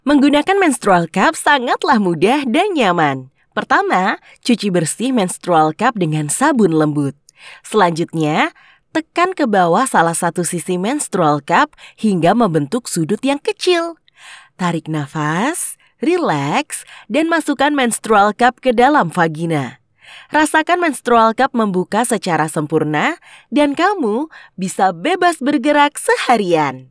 Commercieel, Diep, Volwassen, Warm, Zakelijk
Corporate